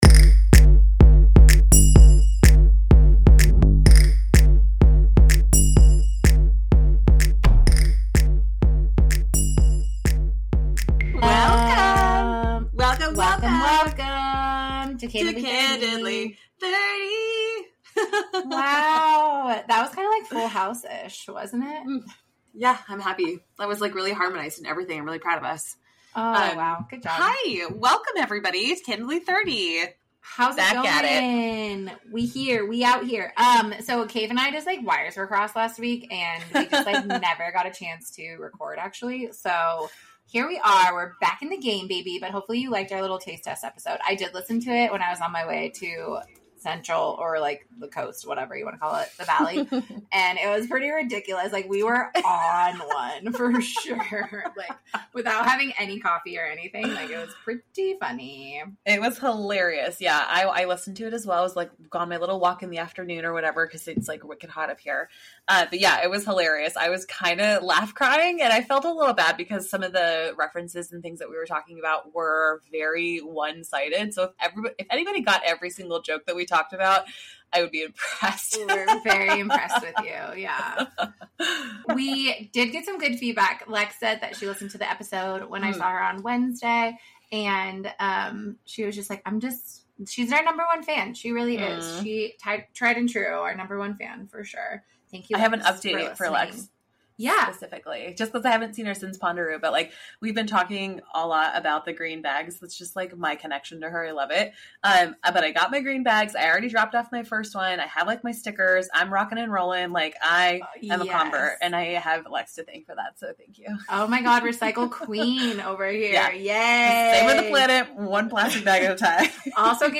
The ladies chat about their top 5 for the month and updates galore!